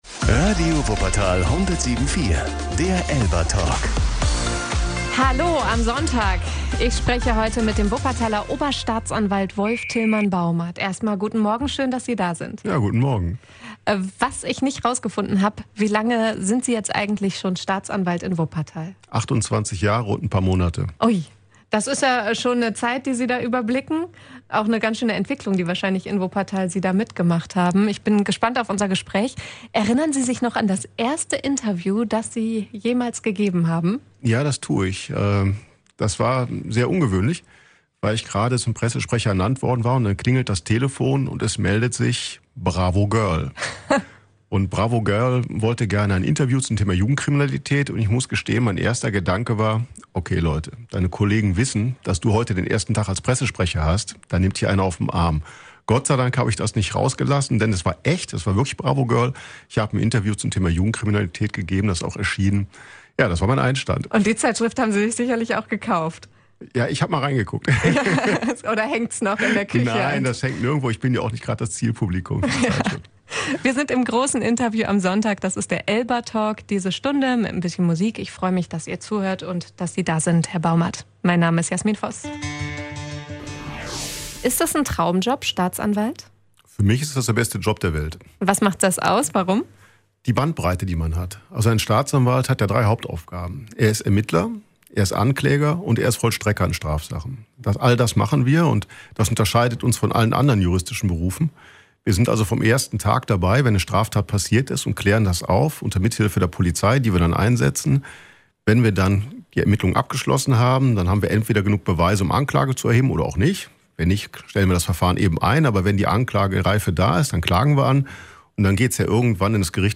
Oberstaatsanwalt Wolf-Tilman Baumert im ELBA-Talk